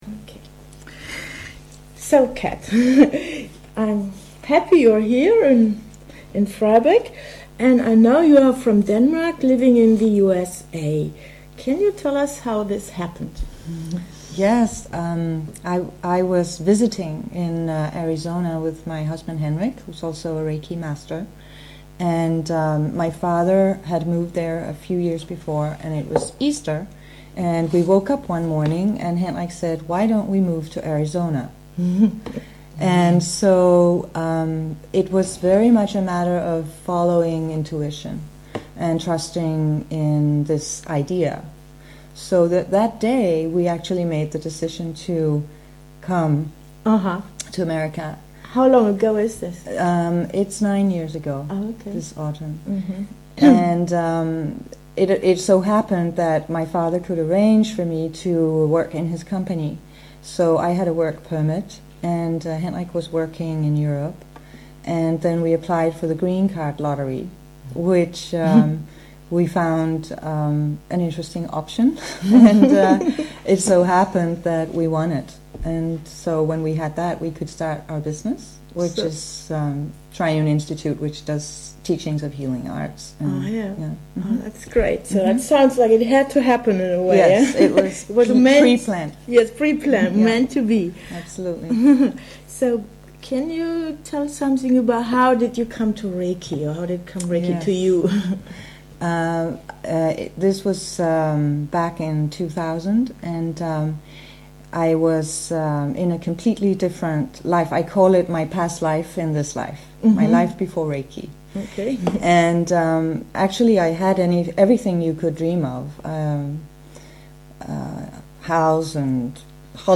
Interview
interview.mp3